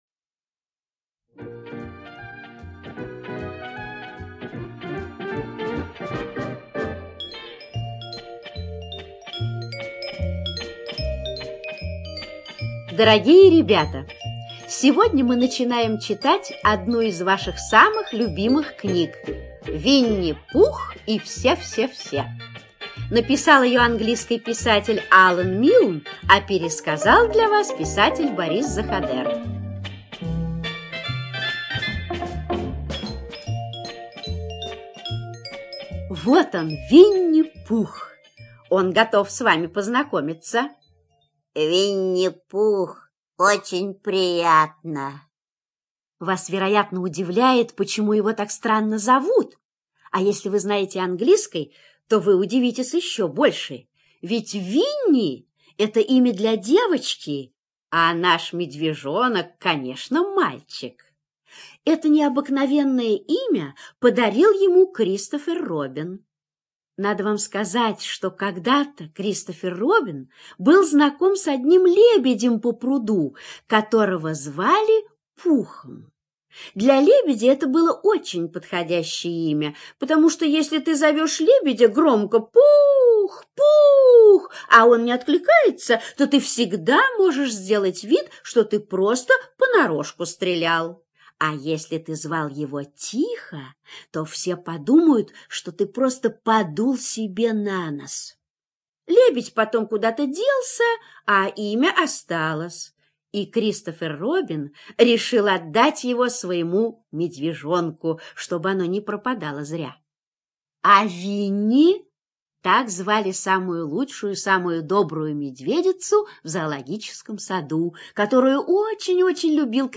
ЖанрДетская литература, Сказки